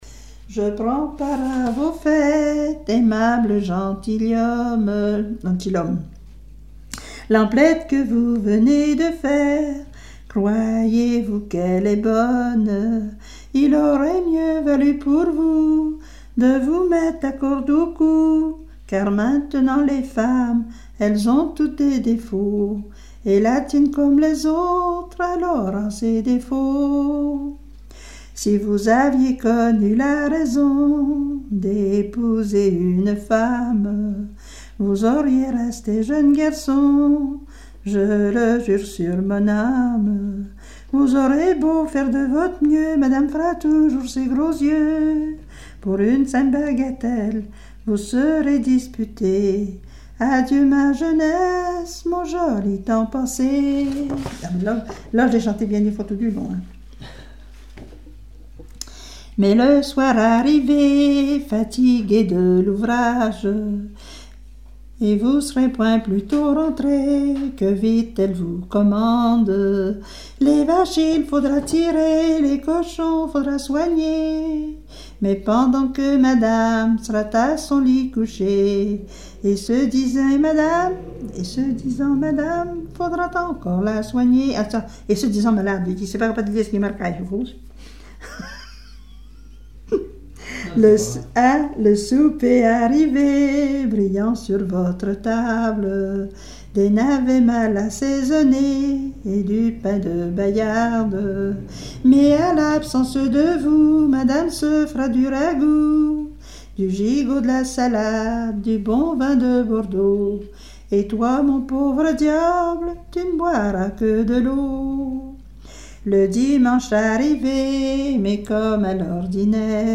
Mémoires et Patrimoines vivants - RaddO est une base de données d'archives iconographiques et sonores.
circonstance : fiançaille, noce
Genre strophique
Pièce musicale inédite